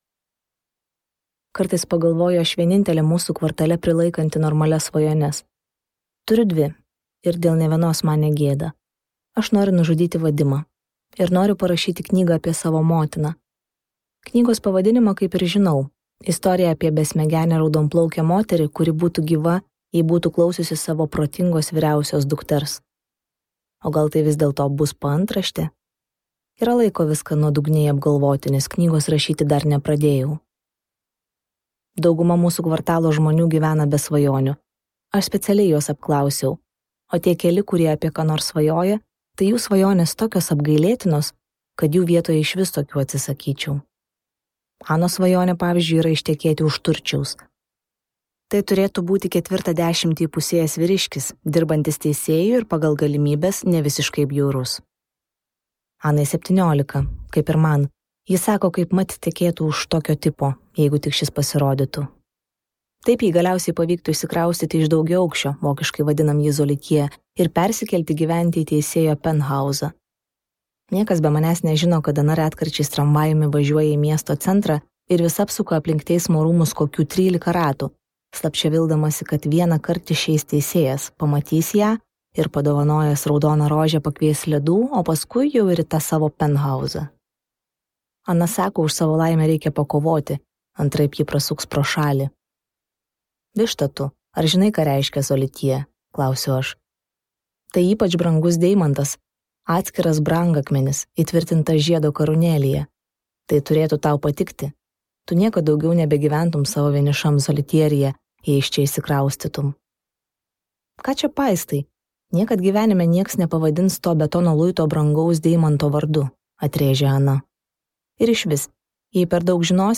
Audio Šukparkis